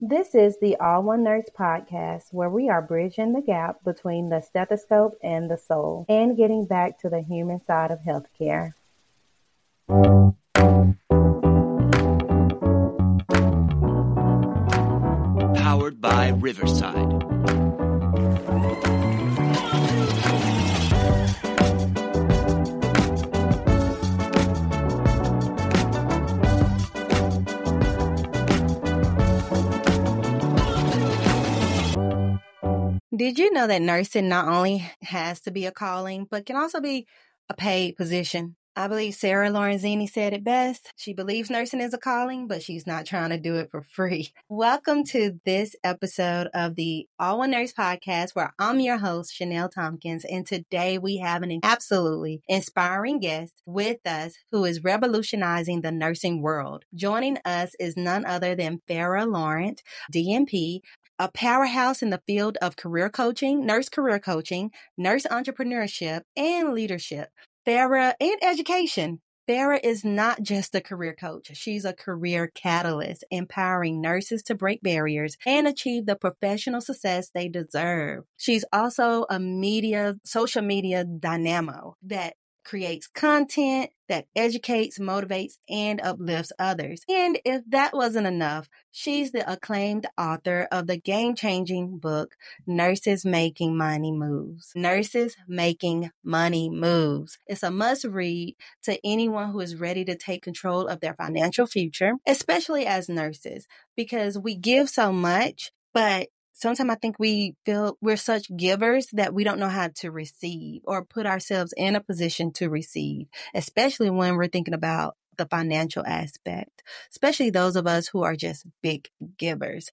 Mentorship, Networking, and Bold Moves: A Conversation